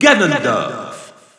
Announcer pronouncing Ganondorf in French.
Ganondorf_French_Announcer_SSBU.wav